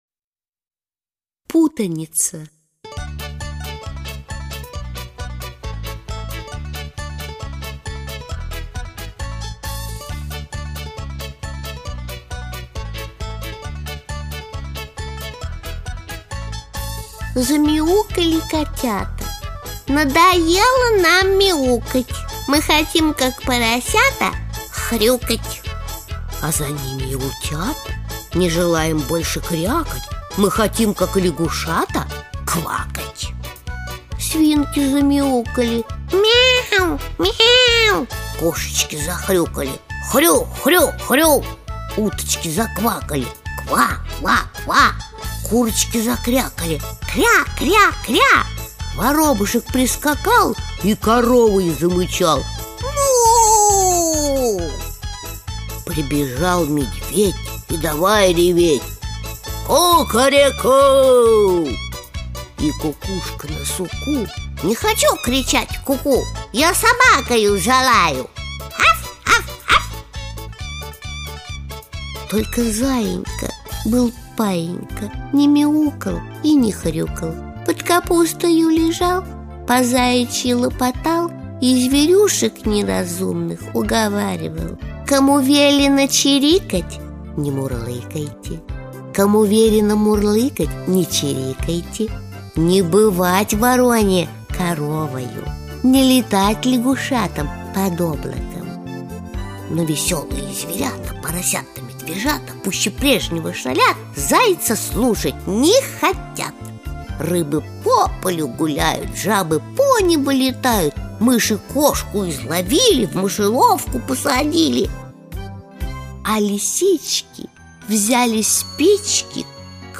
Сказки Чуковского. Скачать, читать и слушать онлайн сказки и стихи
• Исполнители: К. Румянова